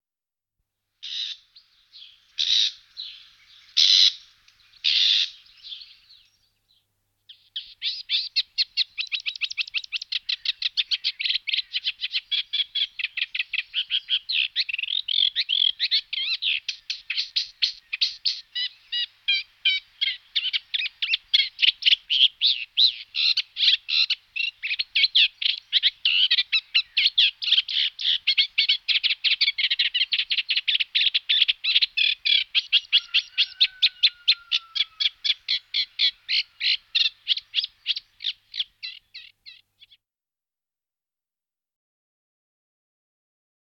Tous les oiseaux d'Europe 3 94 Rousserolle effarvatte Acrocephalis scirpaceus.mp3